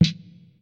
Tag: DR umcomputer 小鼓 葡萄酒